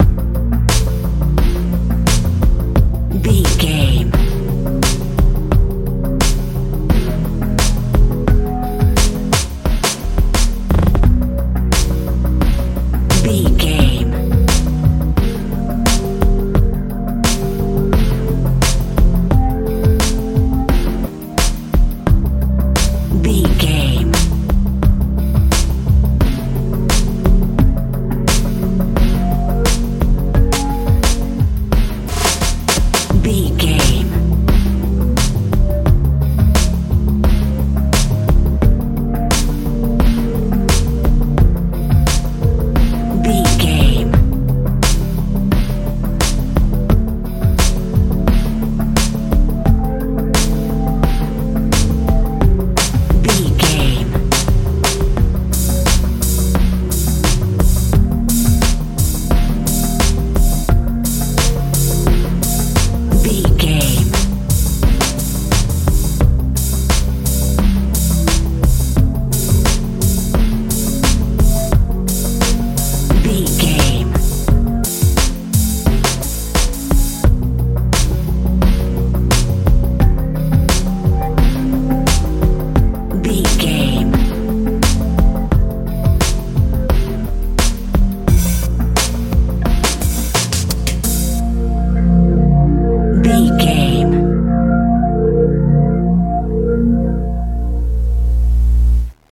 modern pop feel
Ionian/Major
C♯
dreamy
sweet
synthesiser
bass guitar
drums
80s
suspense
strange
soft